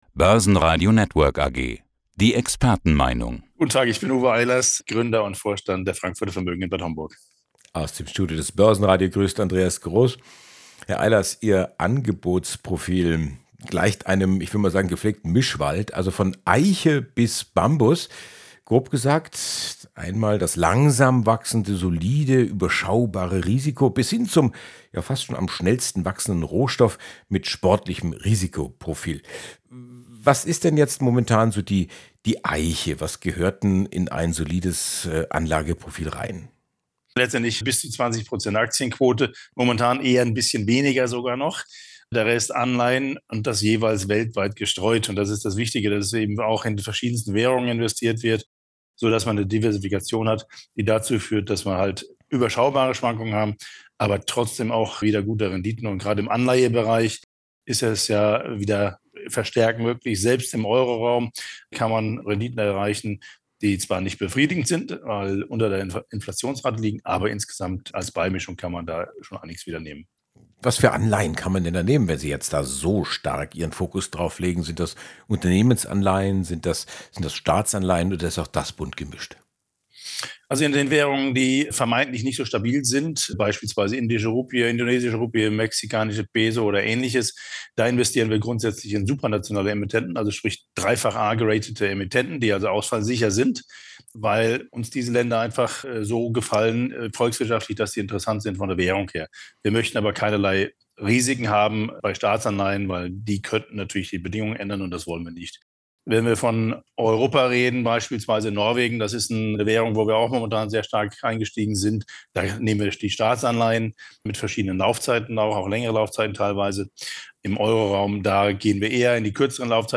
Radiobeitrag